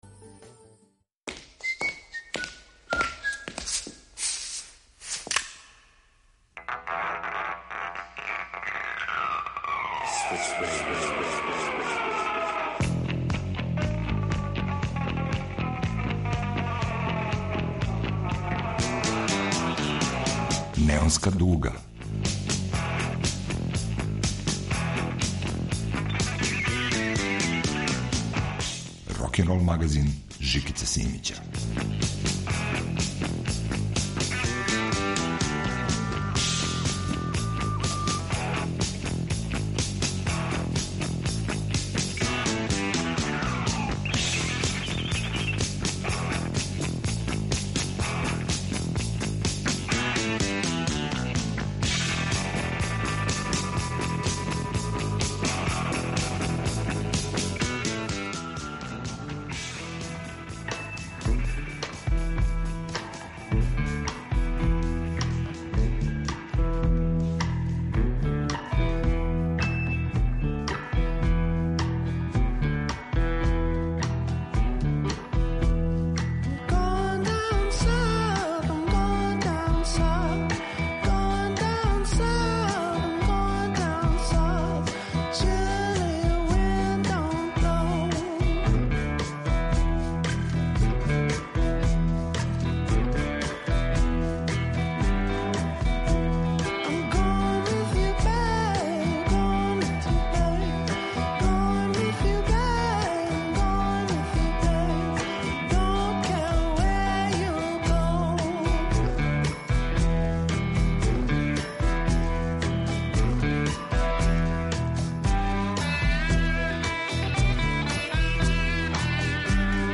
рокенрол магазин
Рокенрол као музички скор за живот на дивљој страни.